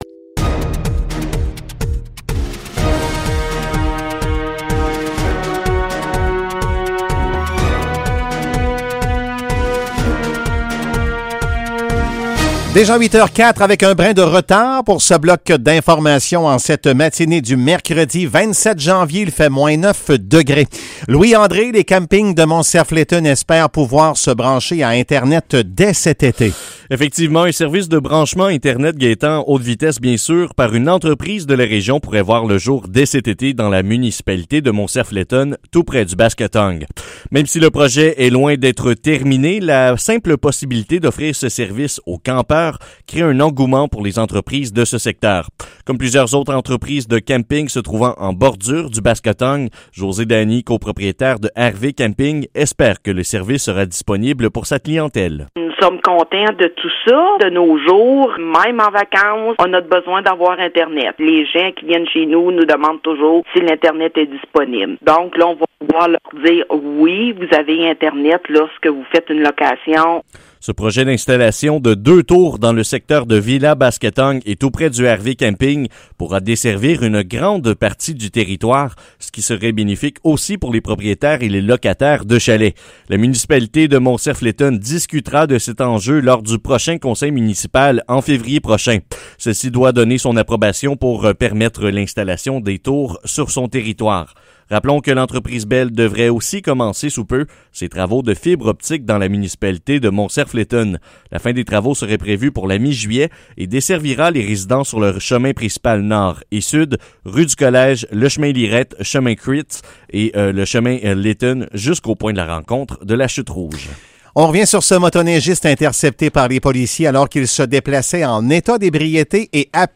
Nouvelles locales - 27 janvier 2021 - 8 h